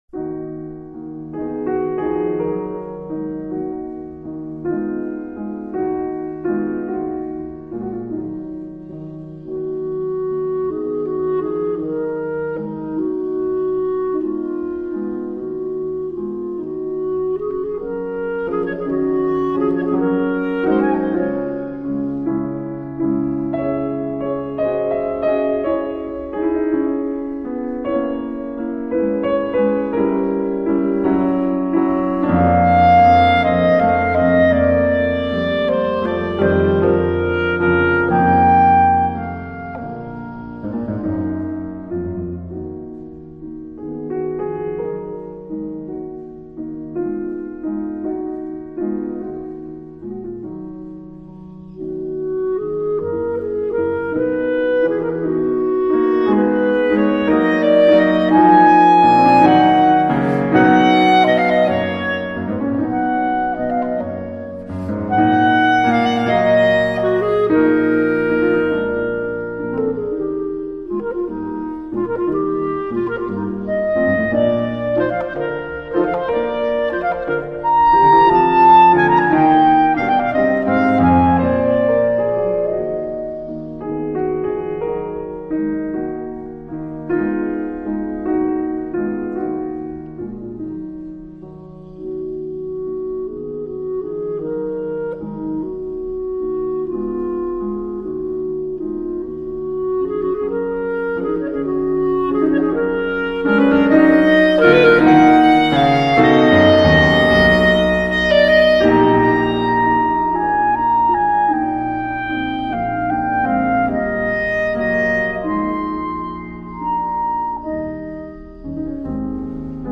- clarinette :